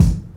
Kick 11.wav